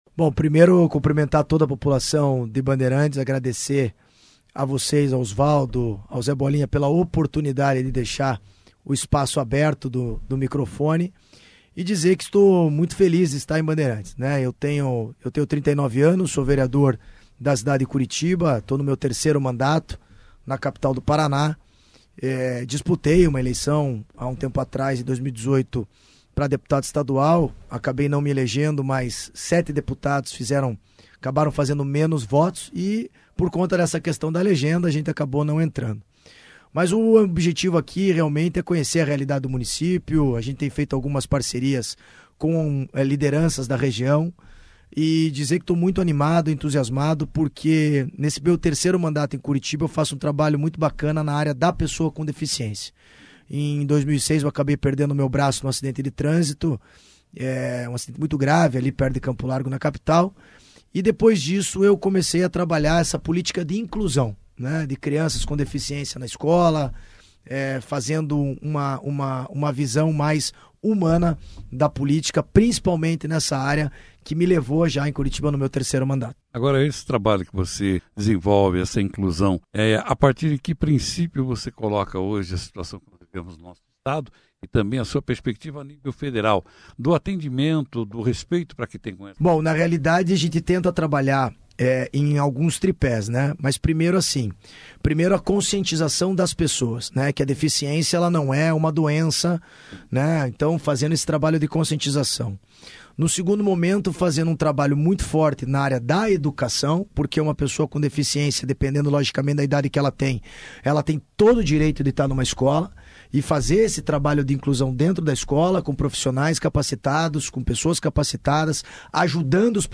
Na oportunidade deu uma entrevista levada ao ar nesta sexta-feira, 13, no jornal Operação Cidade, onde falou sobre seu trabalho no legislativo desde 2013, e de suas pretensões na disputa por uma vaga de deputado federal este ano pelo Partido Progressista.